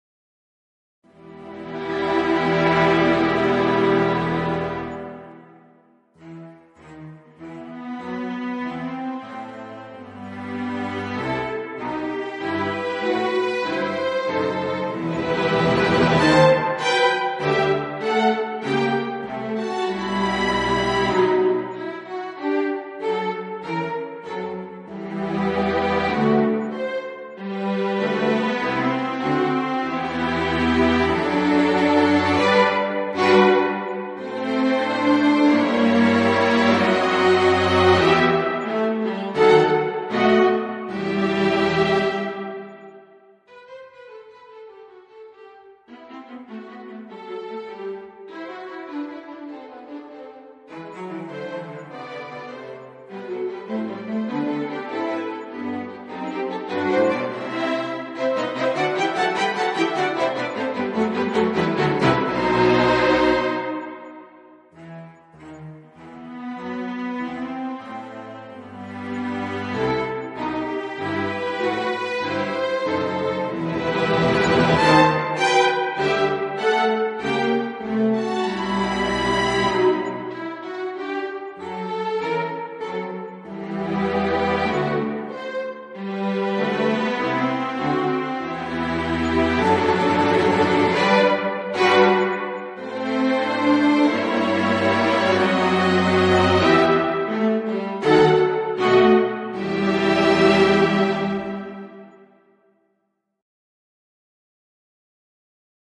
Textura polifónica